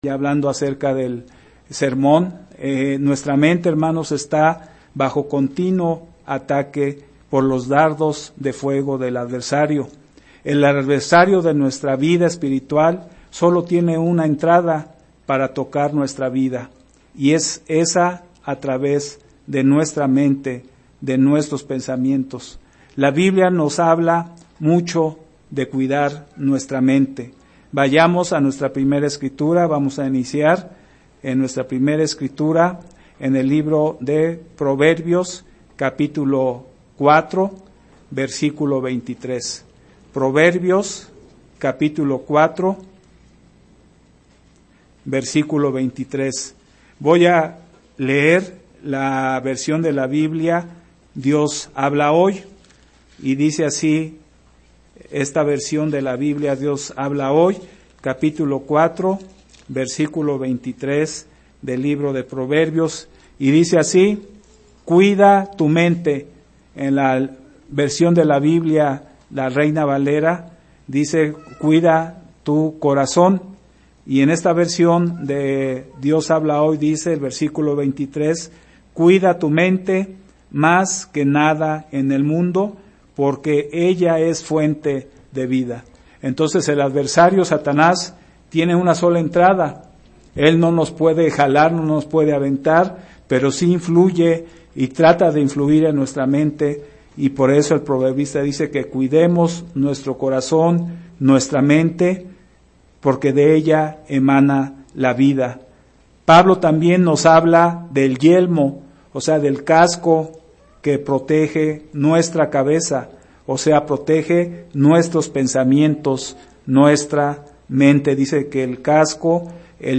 Sermones
Given in Ciudad de México